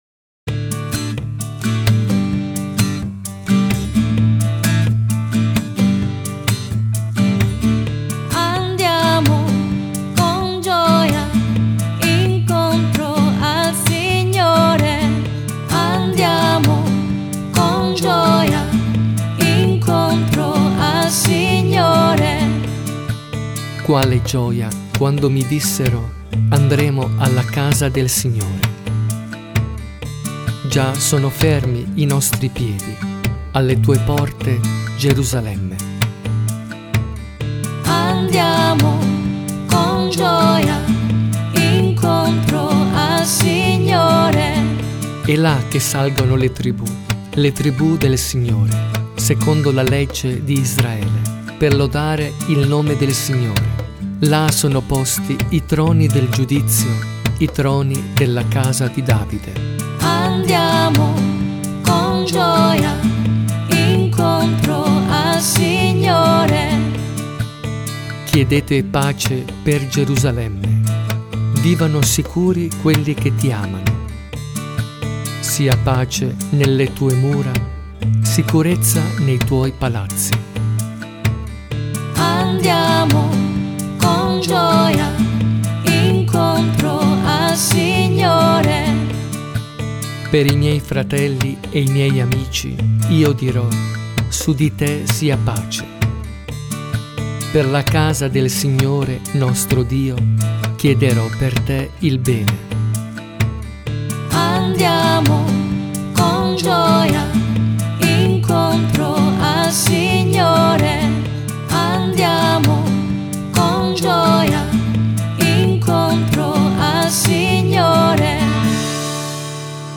SALMO RESPONSORIALE
I DOMENICA DI AVVENTO (ANNO A)